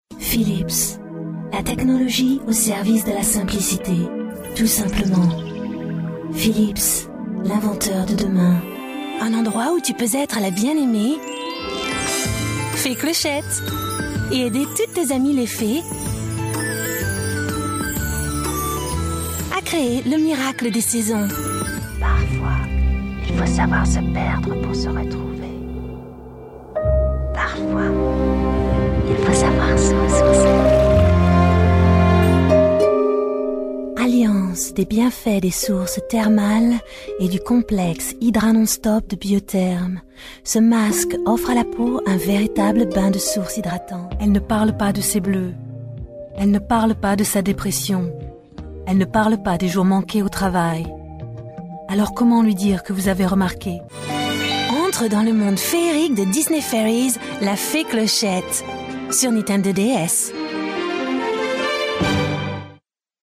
franzĂ¶sische Sprecherin.
She own a recording studio of good quality (Neumann microphone, iso-booth, ISDN).
Sprechprobe: Werbung (Muttersprache):
Native female French voice talent.